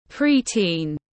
Trẻ em nhi đồng tiếng anh gọi là pre-teen, phiên âm tiếng anh đọc là /ˌpriːˈtiːn/.
Pre-teen /ˌpriːˈtiːn/